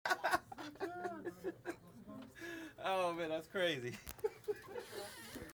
Laughter